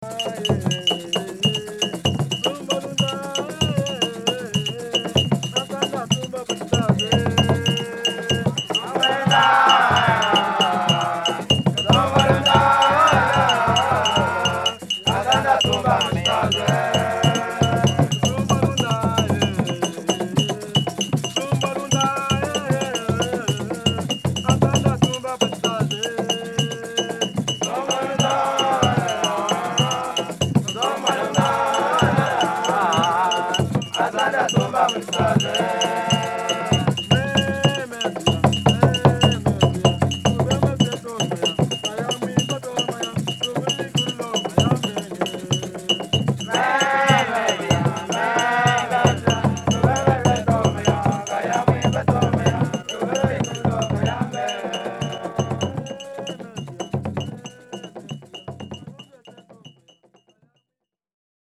Rhythm stick
As regards sound, a clear and high pitch is preferred, supplementing the rest of the percussion, or to provide a rhythmical pulse for group singing. Generally the pattern is the same throughout the entire song and sometimes it is no more than a beat on each count.
This type of instrument is used in recordings of our sound archives made with the Congolese peoples mentioned hereafter where it appears with the following vernacular names: